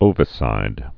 (ōvĭ-sīd)